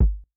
Kick Wilshire.wav